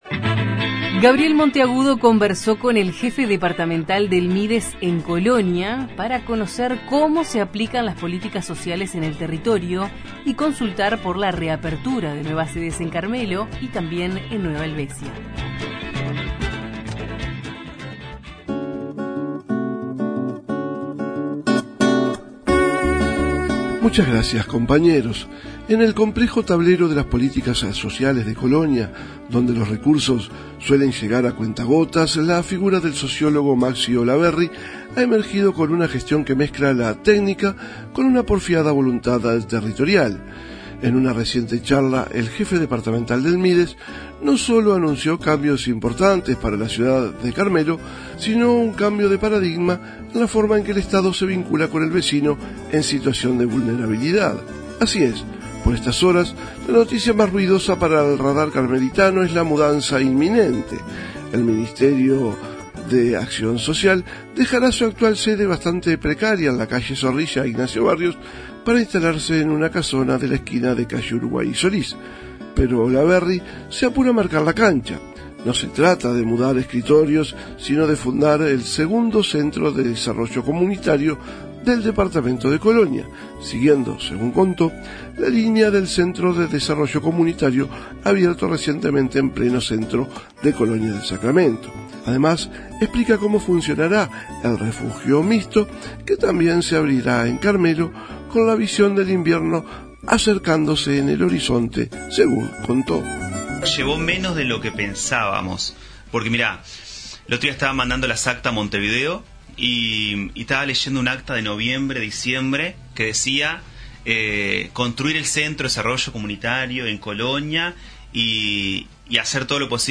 Los informes de nuestros corresponsales en Tacuarembó, Colonia (Oeste) y Río Negro.